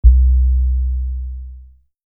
Infra Low Bass Prophet 5 C3 low_bass
low_bass.mp3